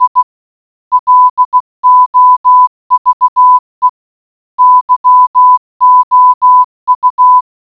Я люблю тебя в коде Морзе Аудио
Вот аудиоклип «Я люблю тебя» в точках и тире…